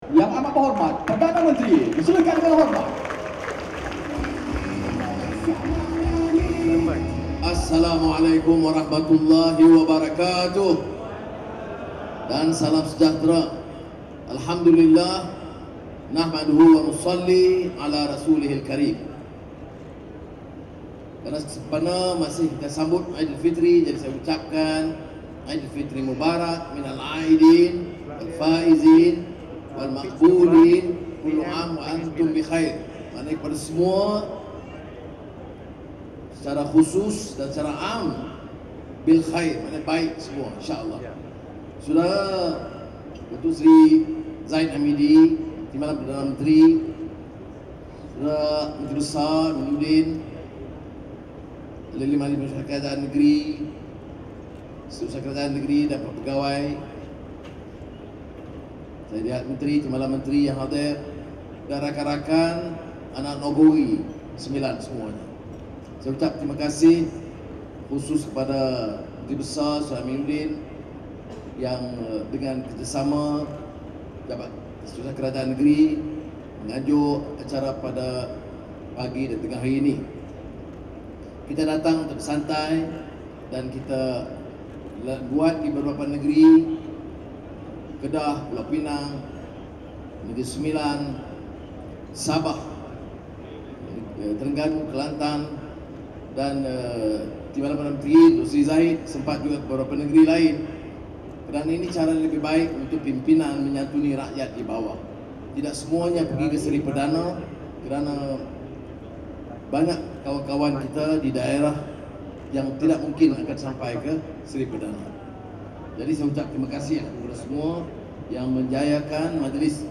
Perdana Menteri, Datuk Seri Anwar Ibrahim serta Jemaah Menteri Kerajaan Perpaduan mengadakan siri Rumah Terbuka Hari Raya Aidilfitri di Dewan Majlis Bandaraya Seremban, Jalan Yam Tuan, Seremban, Negeri Sembilan.